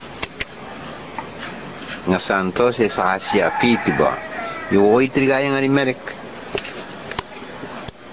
telling this true story from 1972 in Tobian